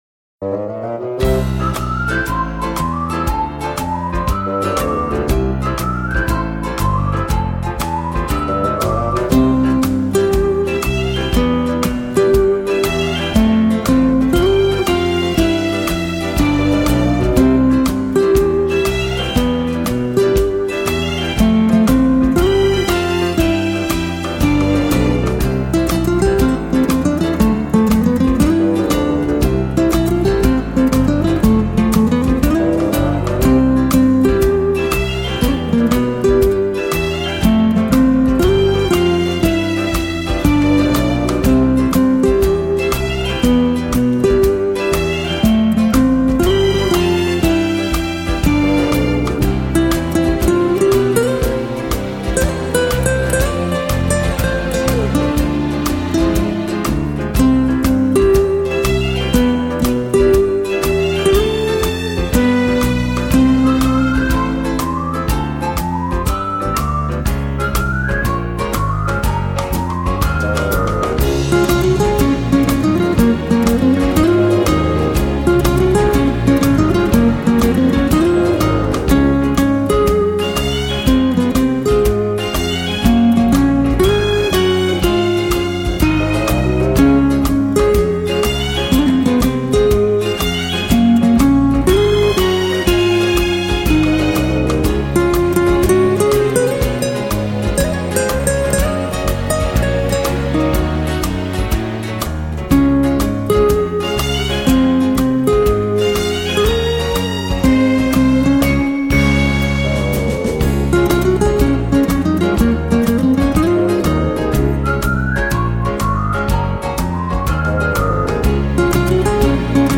радостная и позитивная музыка